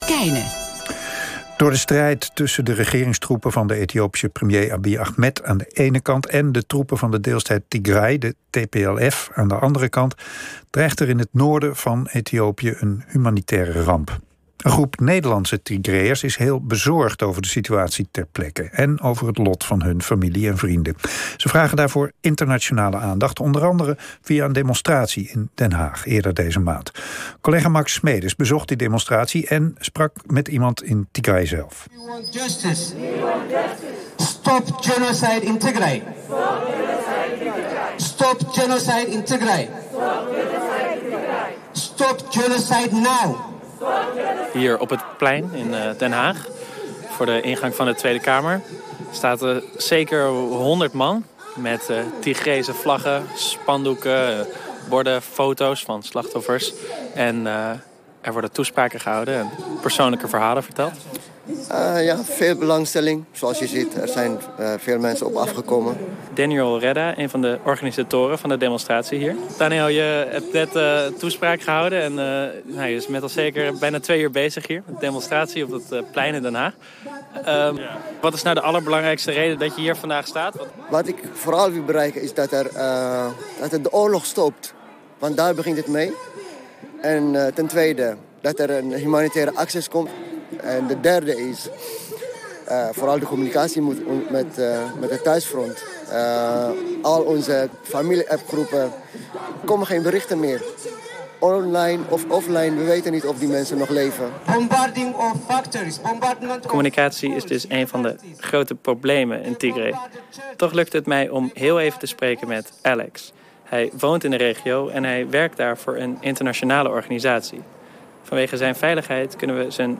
In-Tigray-is-aan-alles-tekort--Bureau-Buitenland--NPO-Radio-1.mp3